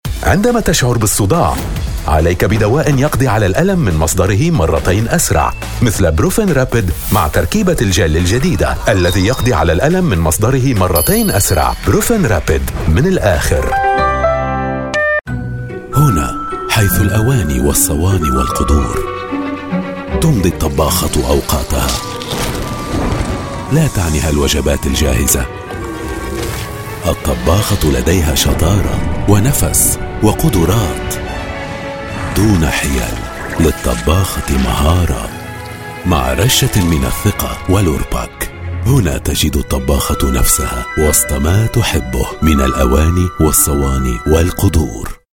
Arapça Seslendirme